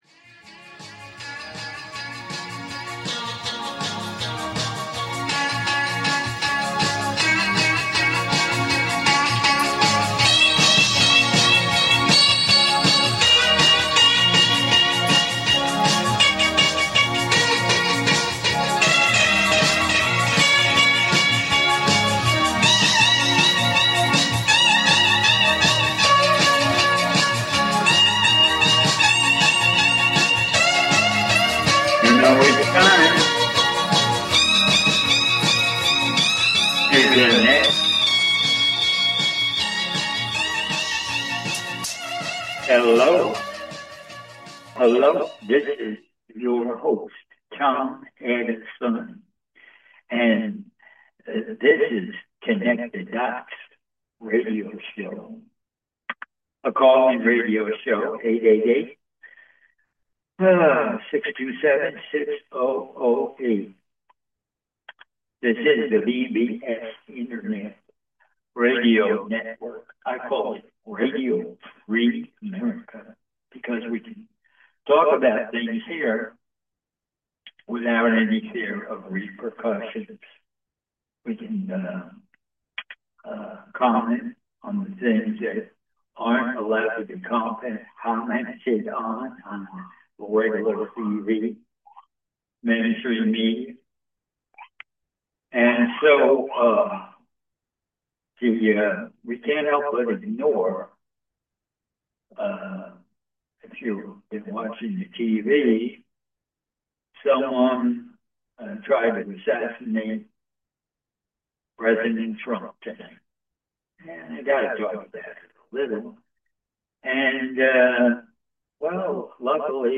Talk Show Episode, Audio Podcast, Connect The Dots and Political Unrest, Galactic Federations, and Tachyon Technology on , show guests , about Political Unrest,Galactic Federations,Tachyon Technology,Political Commentary,Science of Mind Control,Galactic Landscape,Galactic Federation,Ashtar,Tachyon Chambers,Pleiadian Experimentation,Economic Critique,Sacred Geometry, categorized as Earth & Space,Entertainment,News,Paranormal,Physics & Metaphysics,Politics & Government,Society and Culture,Spiritual,Theory & Conspiracy